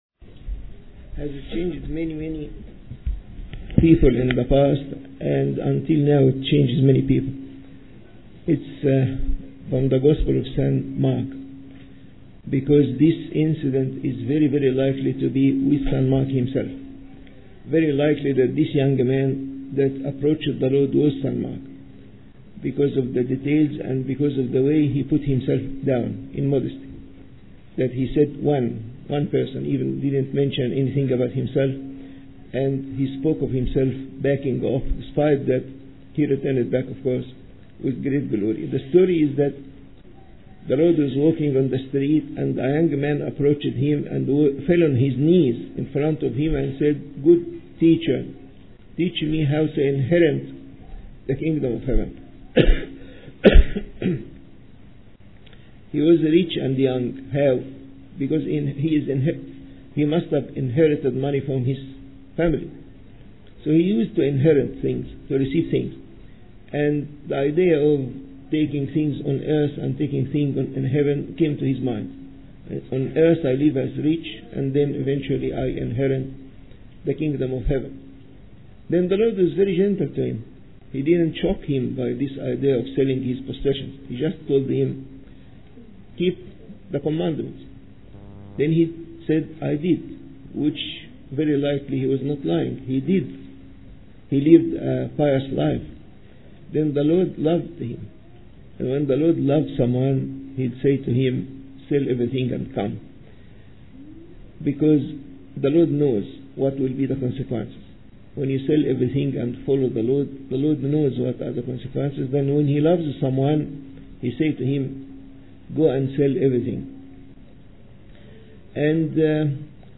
English Sunday Sermon